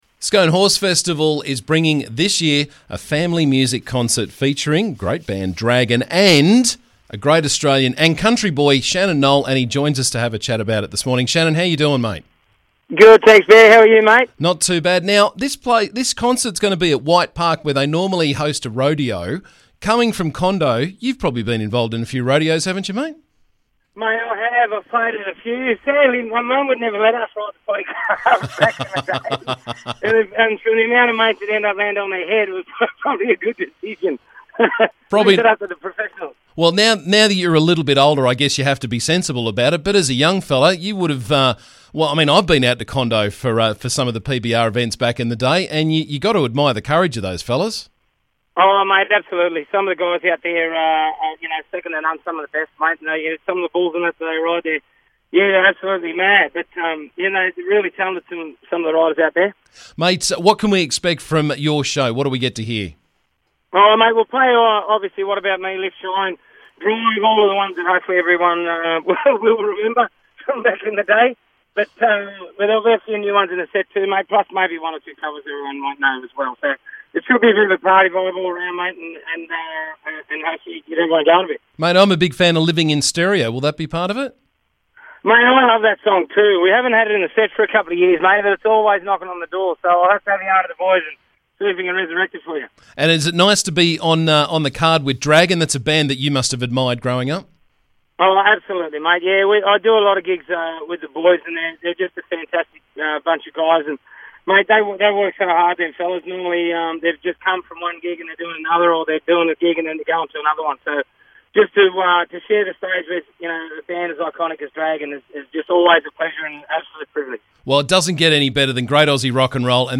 Shannon Noll joined me to catch up and talk about their upcoming concert at White Park on May 6th.